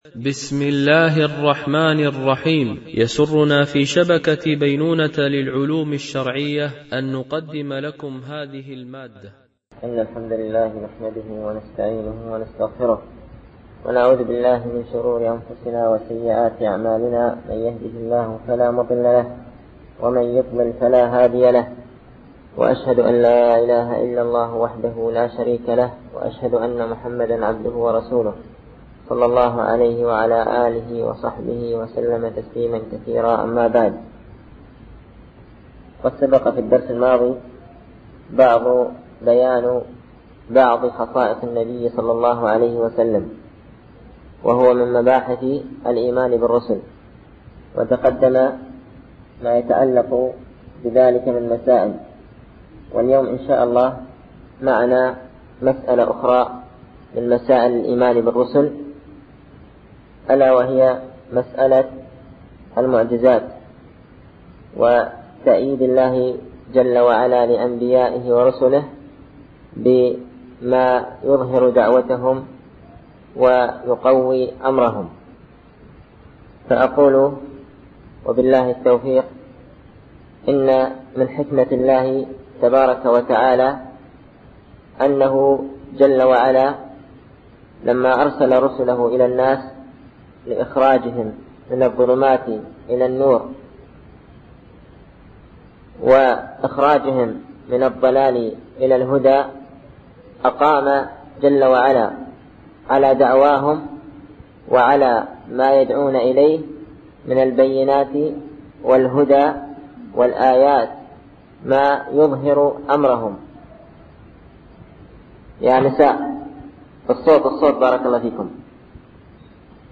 شرح أعلام السنة المنشورة ـ الدرس 36 ( ما هي معجزات الأنبياء ؟)